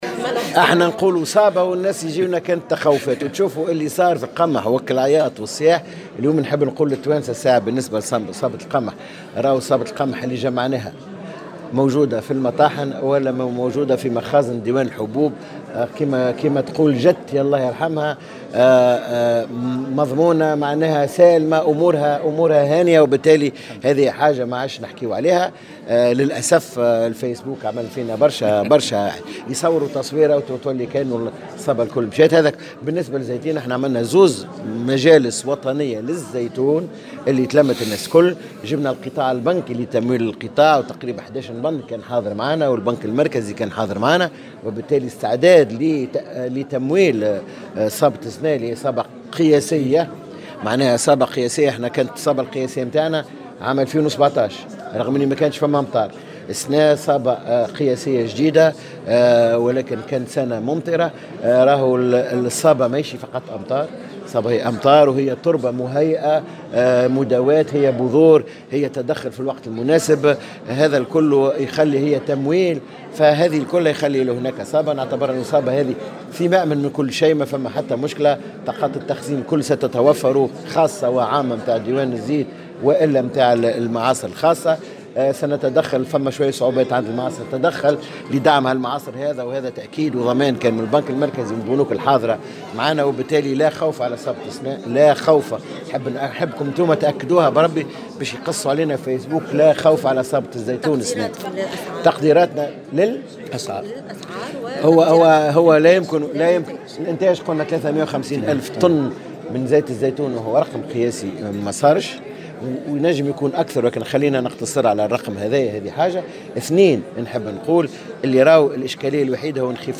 قال وزير الفلاحة سمير بالطيب في تصريح لمراسل الجوهرة "اف ام" اليوم الإثنين إن صابة الزيتون لهذا العام ستكون قياسية و حتى أن التقديرات بخصوصها تتجاوز تلك التي تم تحقيقها في سنة 2018 .